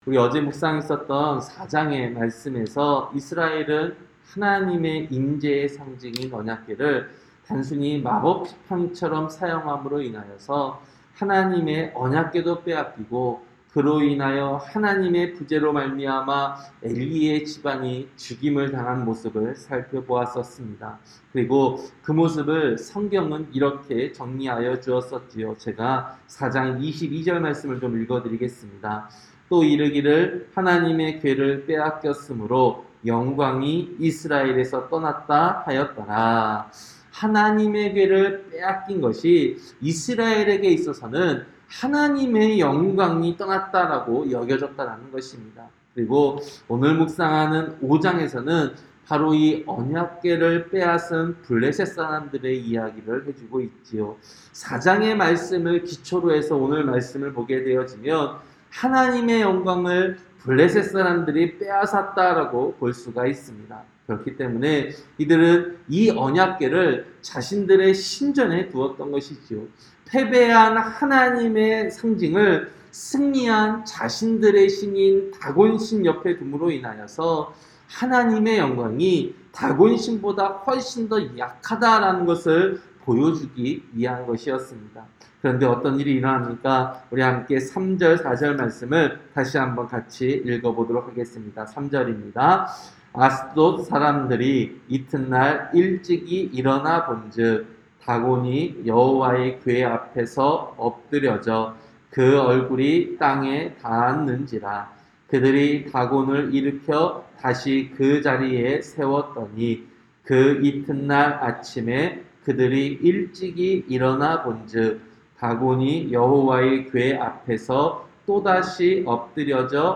새벽설교-사무엘상 5장